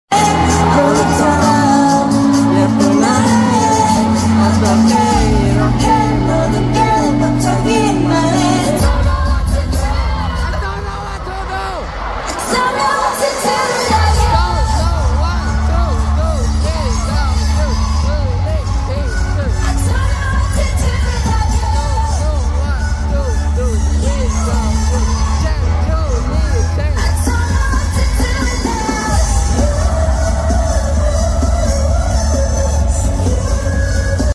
fanchant